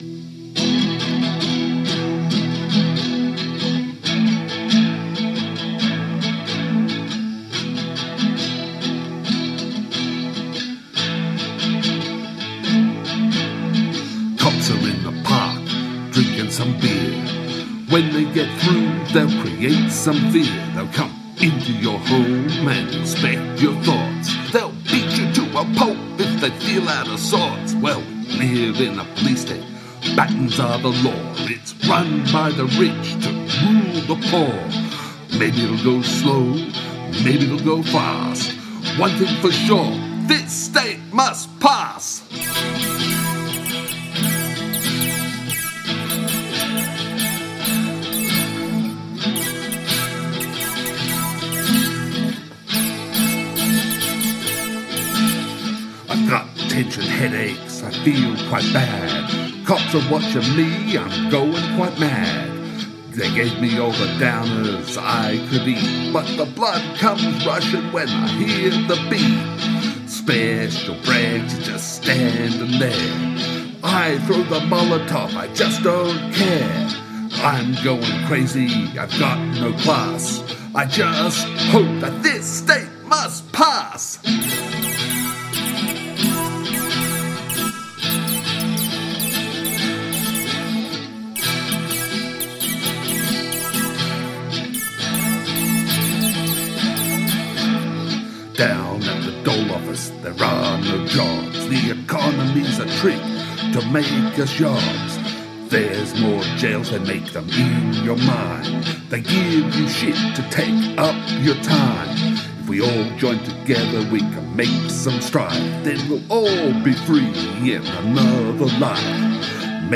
unplugged versions